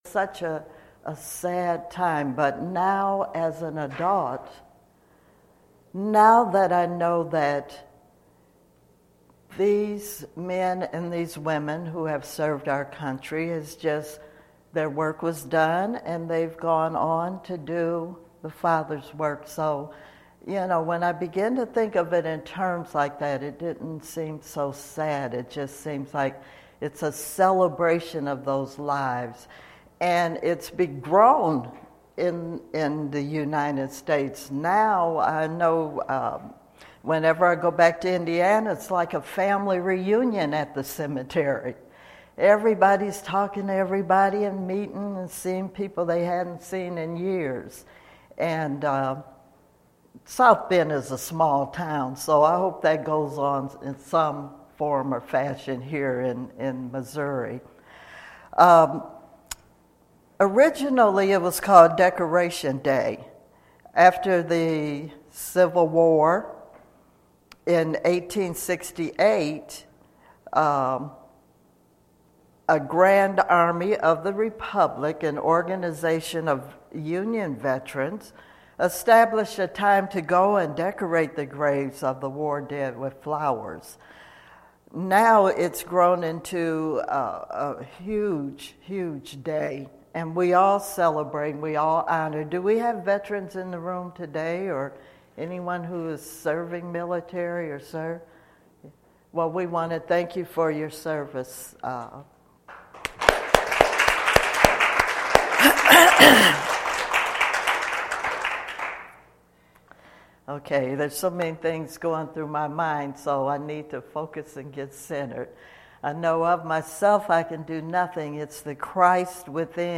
Series: Sermons 2015